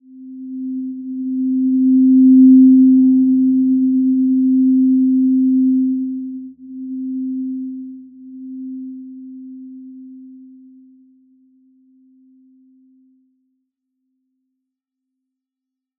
Simple-Glow-C4-f.wav